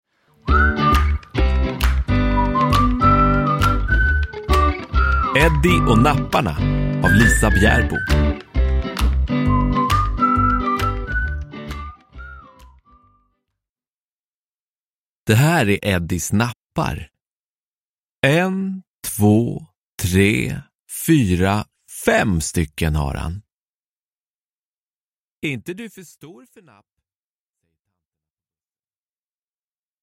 Eddie och napparna – Ljudbok – Laddas ner